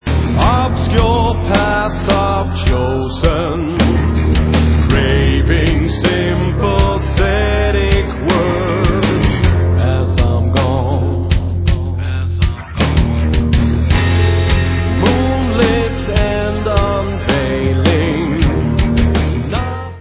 Hard Rock